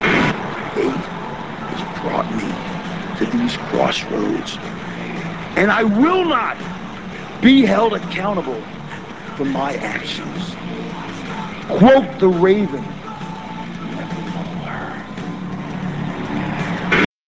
raven73.rm - This clip comes from WWE Heat - [06.09.02]. Raven tells Terri that fate has brought him to a crossroads and he won't be held accountable for his actions.